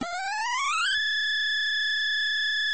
suction.ogg